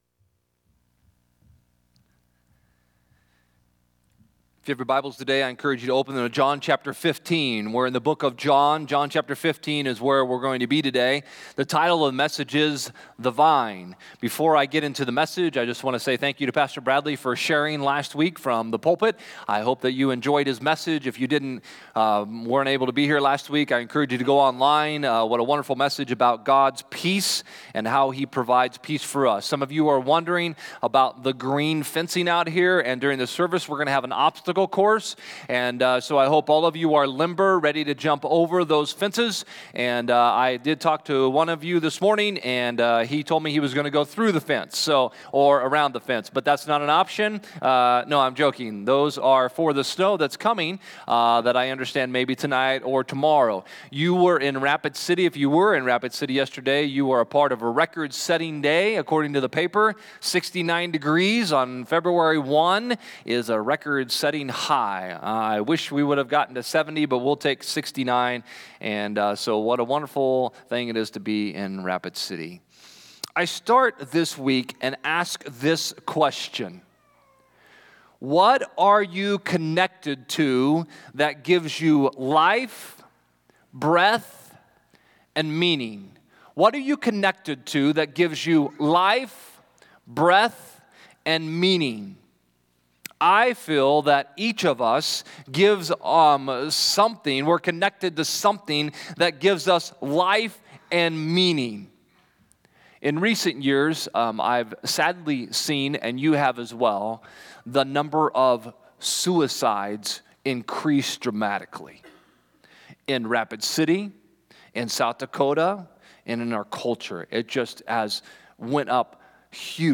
Click Here to Follow Along with the Sermon on the YouVersion Bible App Romans 1:26-27 English Standard Version 26 For this reason God gave them up to dishonorable passions.